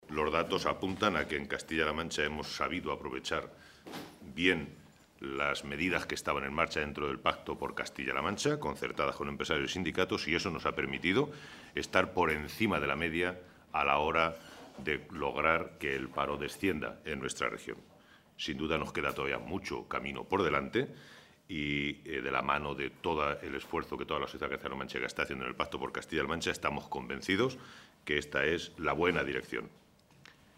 Santiago Moreno, portavoz del Grupo Parlamentario Socialista
Cortes de audio de la rueda de prensa